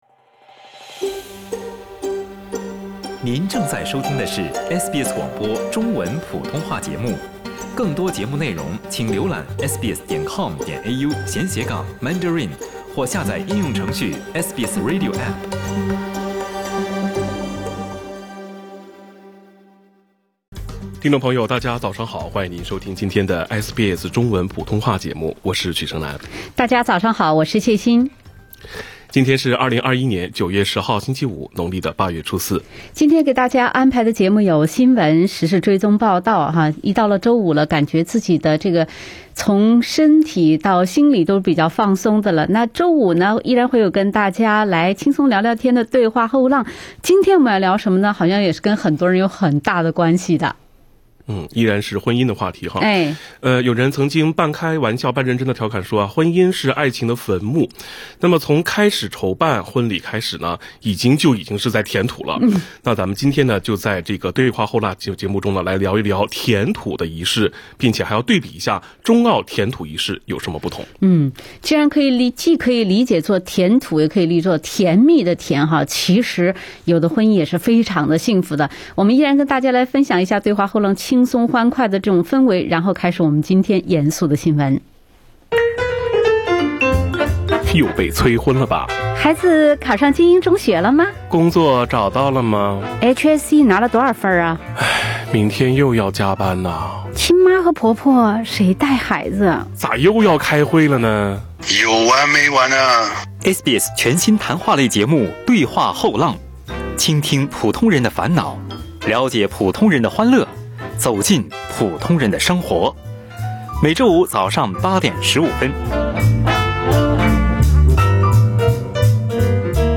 SBS早新聞（9月10日）
SBS Mandarin morning news Source: Getty Images